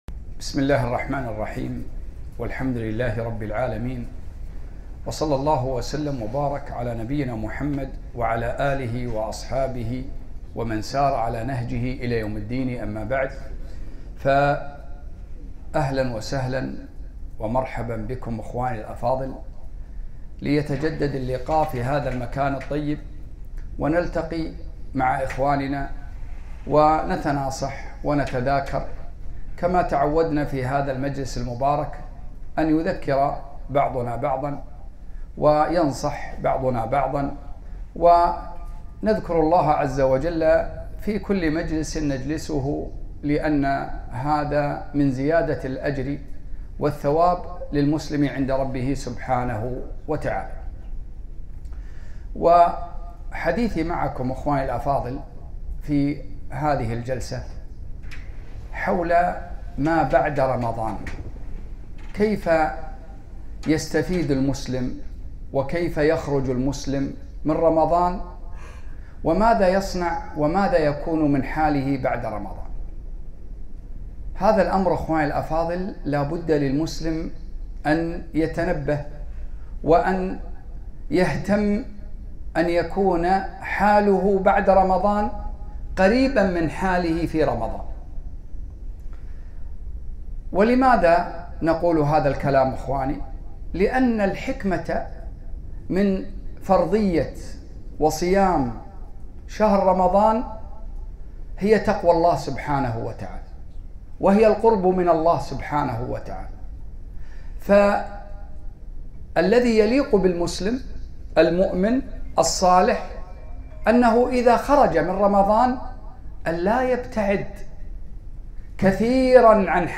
محاضرة - حـال الـمـسـلـم بـعـد رمـضـــان